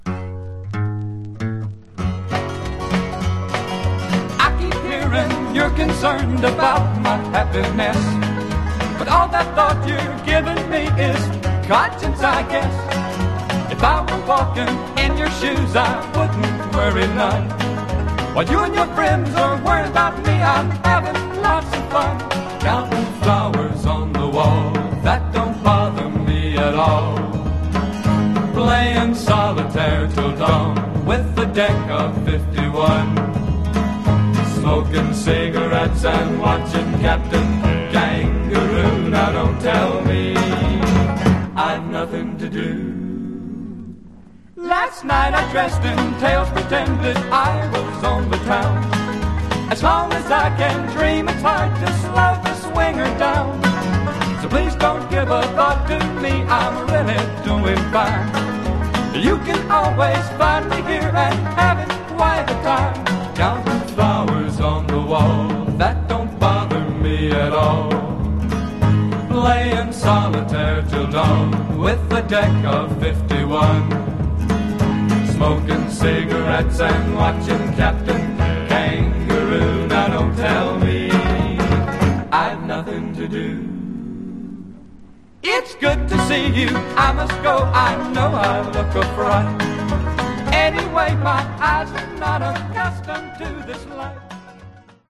Genre: Folk Rock